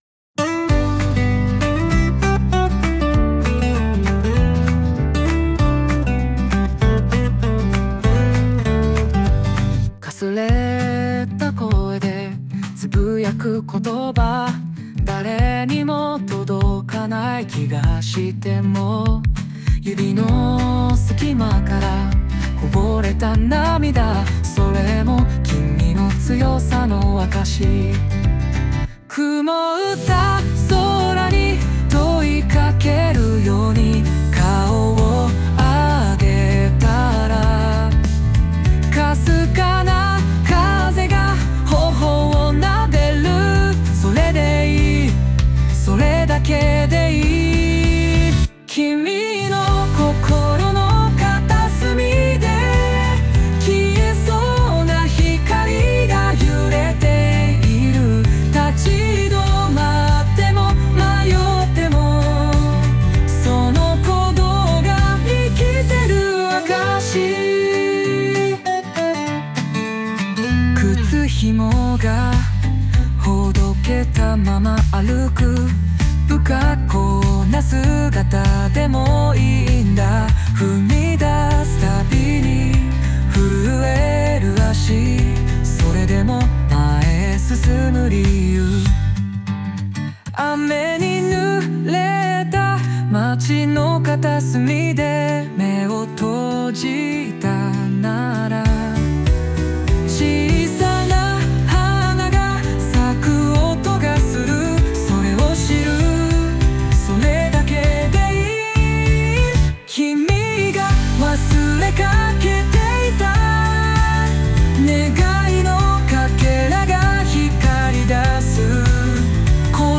邦楽男性ボーカル著作権フリーBGM ボーカル
著作権フリーオリジナルBGMです。
男性ボーカル（邦楽・日本語）曲です。
ぜひ、元気づけるような歌をイメージしました！